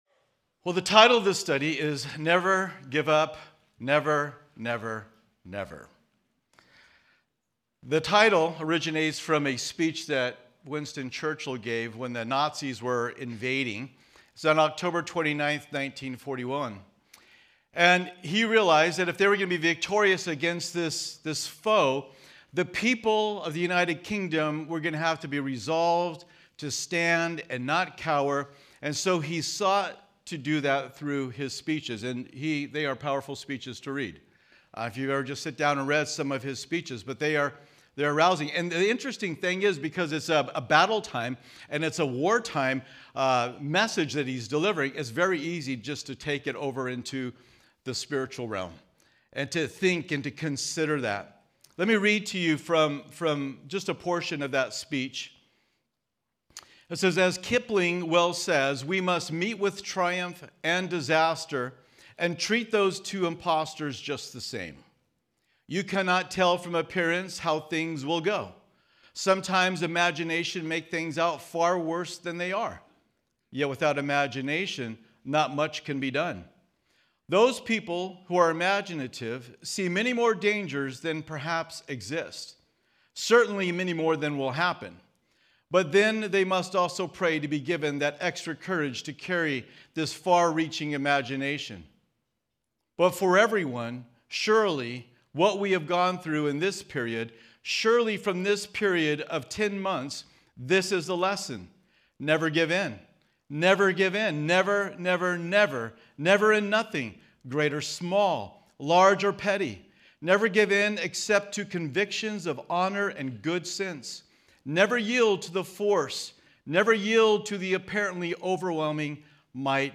Home » Sermons » Never Give Up – Never, Never, Never